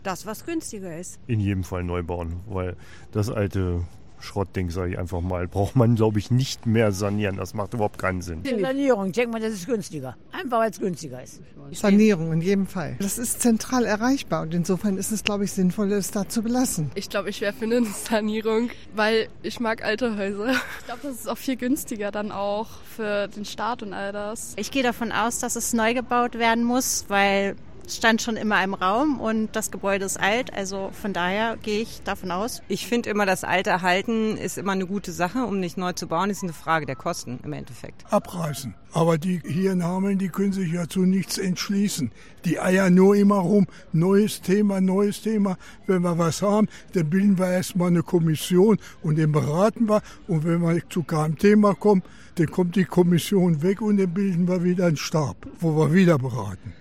Hameln: Umfrage – Soll das Rathaus neugebaut oder doch lieber saniert werden?